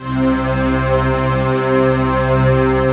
SI1 CHIME05R.wav